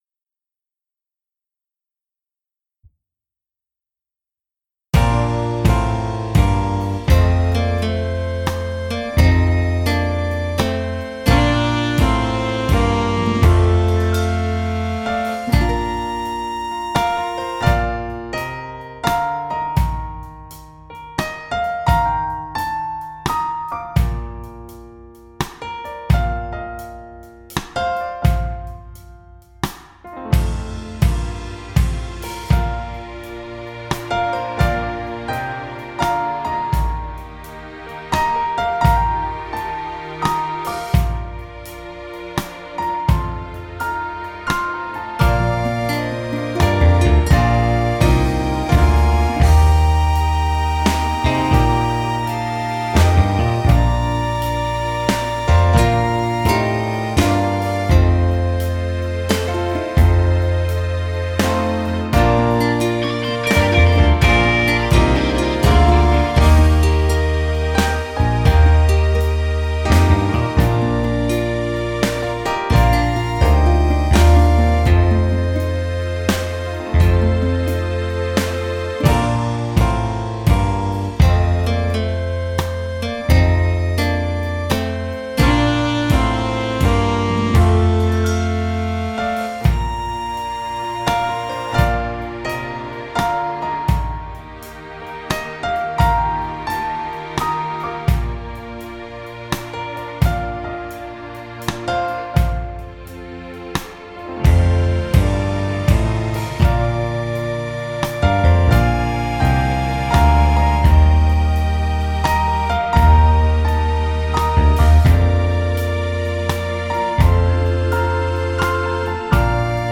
(from MIDI)
Playbacks-KARAOKE Zobrazení